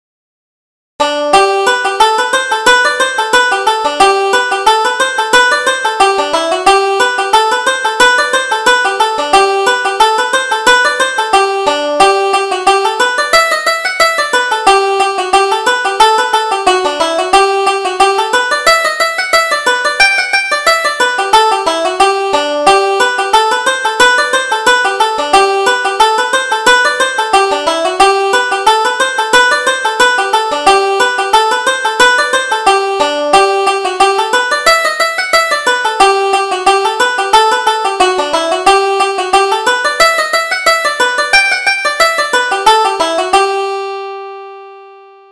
Reel: The Same Old Story